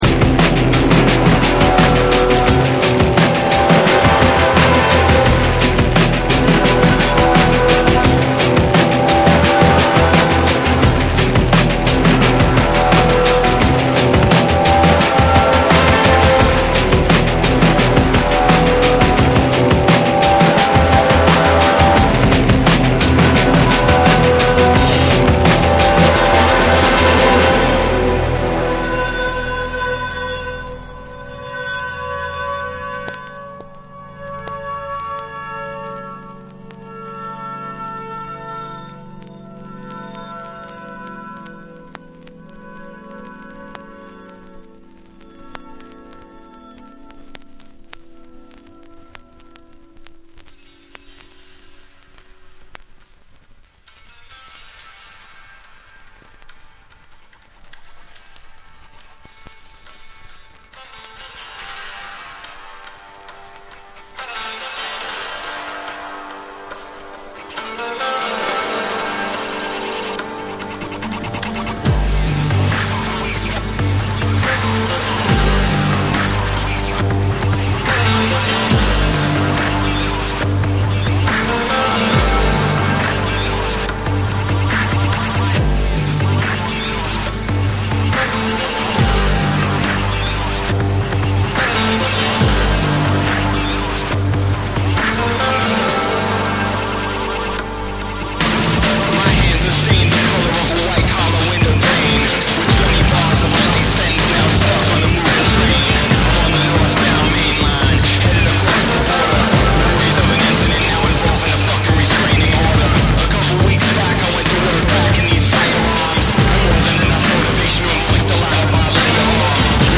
メランコリックで奥行きあるサウンド・プロダクションが光るブレイクビーツ！
BREAKBEATS